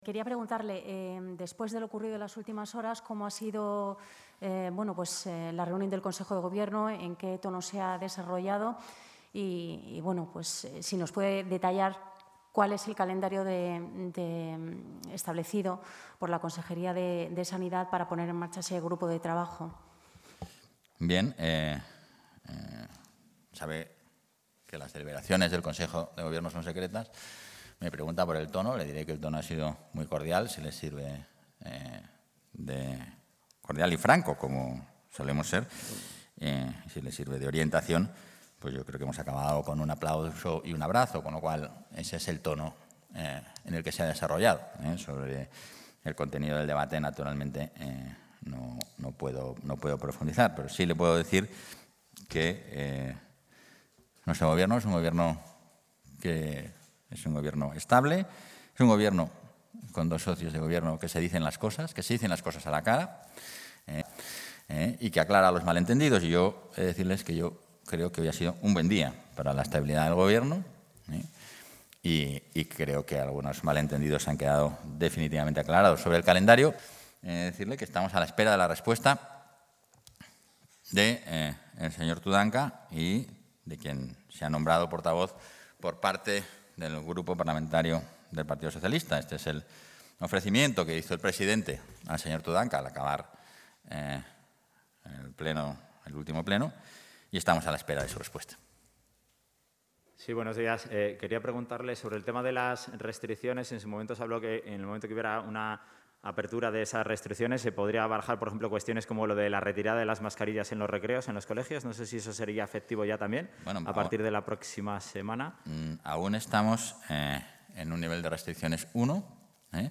Intervención del vicepresidente y portavoz.